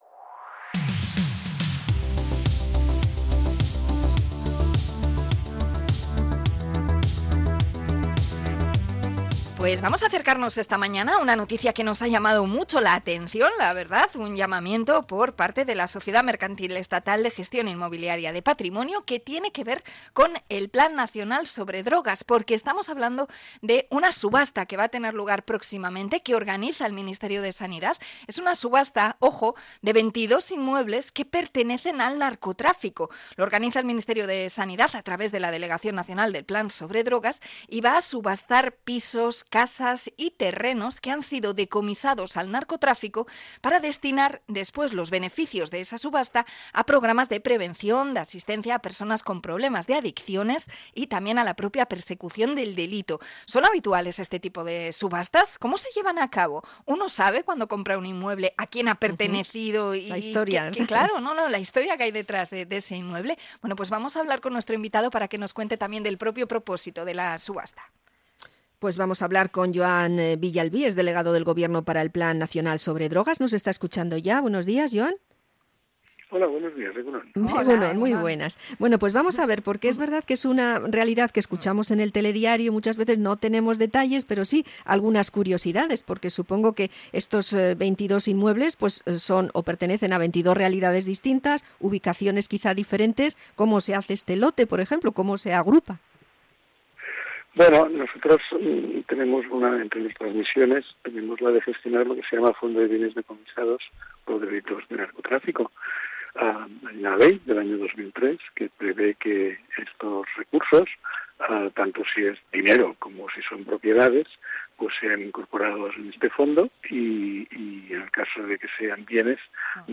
Entrevista al delegado de Gobierno para el plan nacional sobre Drogas